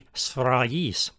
Pronunciation Note: When a single Gamma (γ) is followed by the vowels Epsilon (ε) or Iota (ι), or by the digraph Epsilon-Iota (ει) as in the word σφραγίς, the Gamma is pronounced like the y in yet.